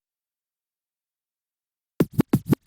scratch